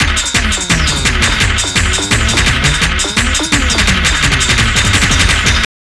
__SLOW LFO 2.wav